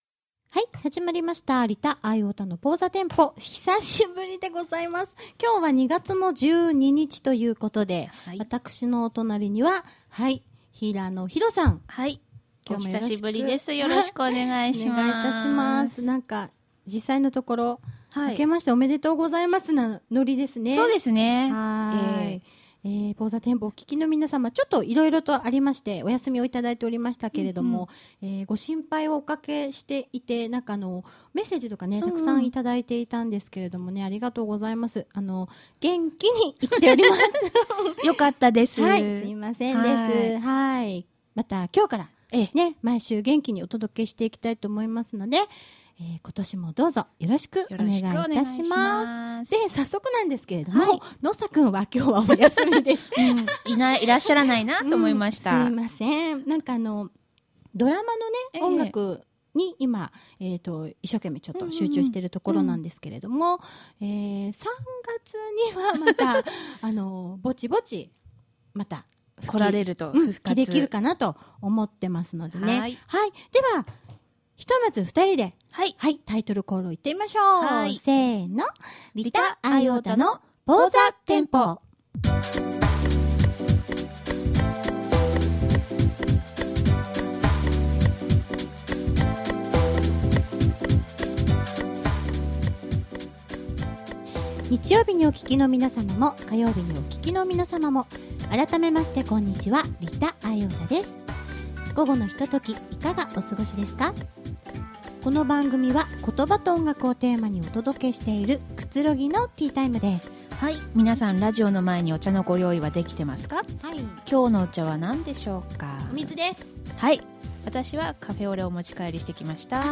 バレンタインにふさわしい女子トークでし。 2012年の運勢をスピトークでし。